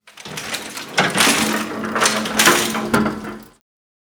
gurneyunload.wav